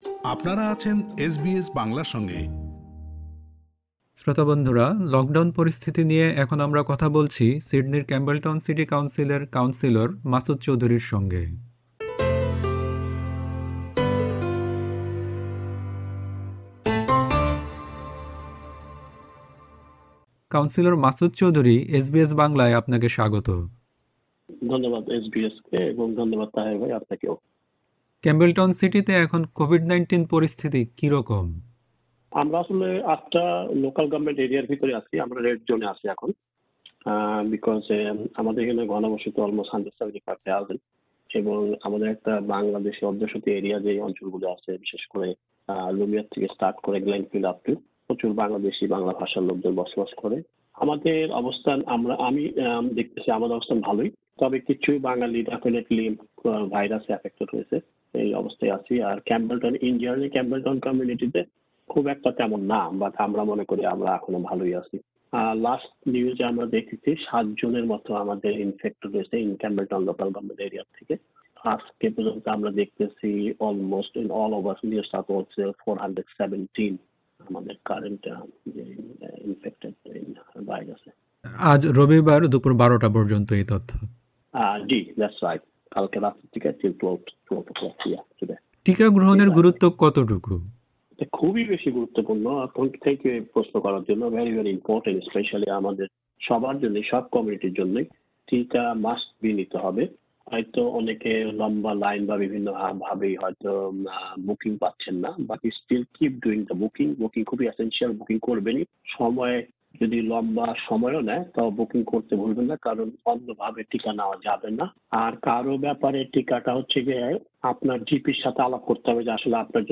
লকডাউন পরিস্থিতি নিয়ে এসবিএস বাংলার সঙ্গে কথা বলেছেন সিডনির ক্যাম্বেলটাউন সিটি কাউন্সিলের কাউন্সিলর মাসুদ চৌধুরী।
Source: NSW Government কাউন্সিলর মাসুদ চৌধুরীর সাক্ষাৎকারটি শুনতে উপরের অডিও-প্লেয়ারটিতে ক্লিক করুন।